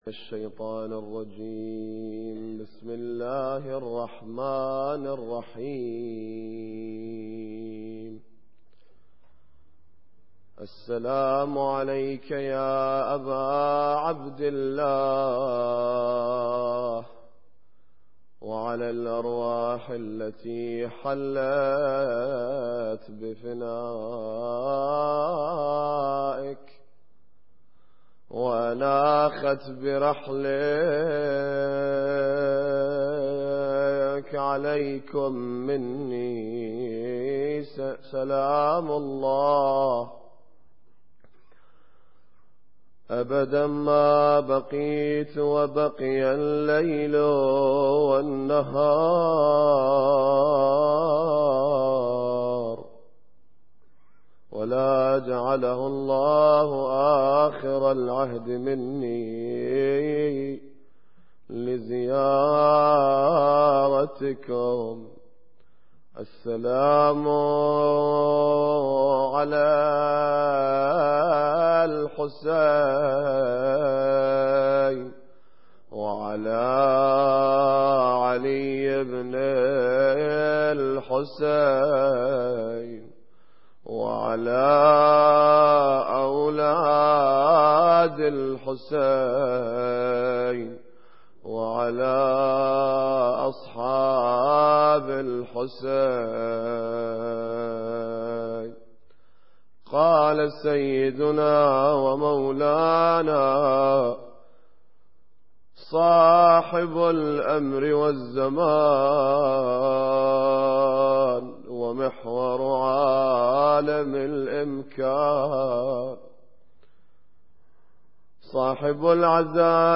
Muharram Lecture 9